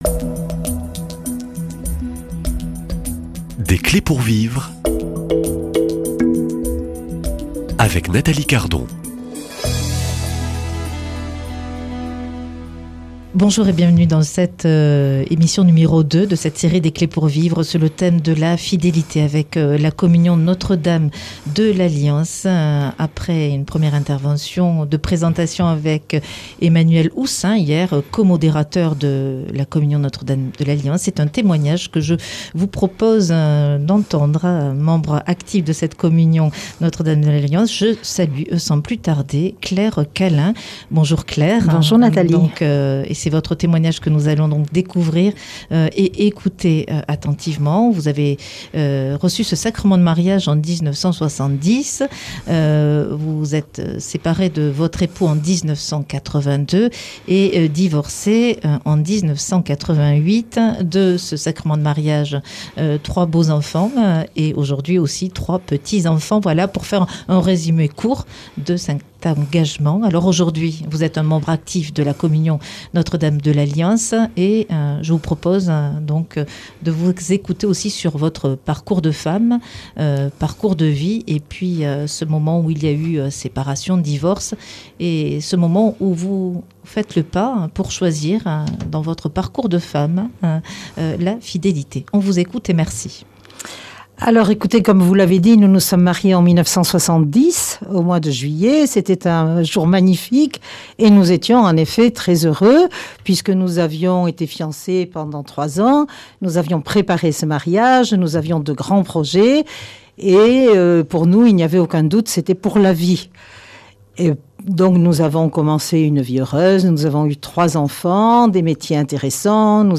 Le chemin intérieur après la séparation Comment dépasser la douleur et trouver la paix intérieure ? Comment pardonner ? Invitée témoin